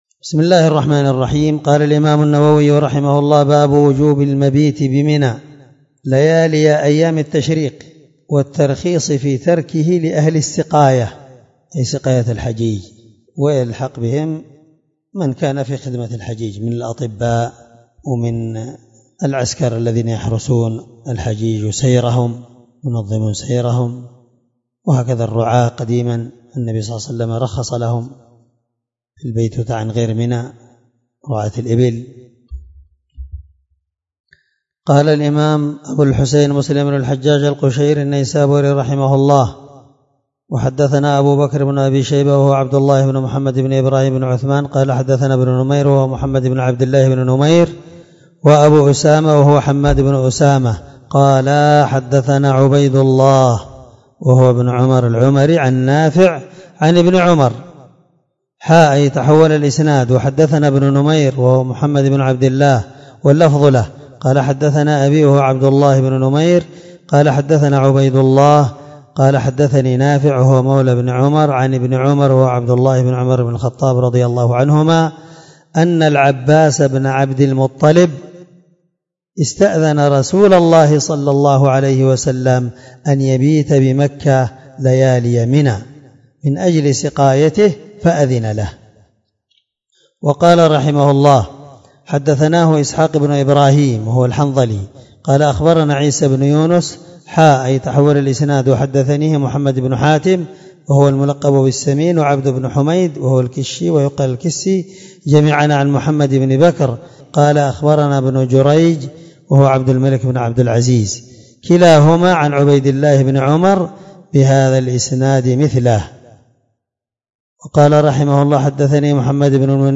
الدرس56من شرح كتاب الحج حديث رقم(1315-1316) من صحيح مسلم